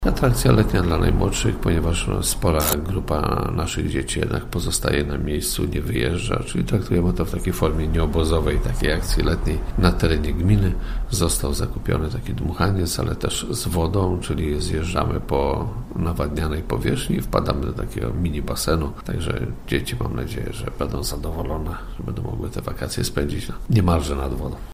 – Już próbne jego uruchomienie sprawiło najmłodszym ogromną frajdę – mówi Wiesław Czyczerski, burmistrz Zbąszynka.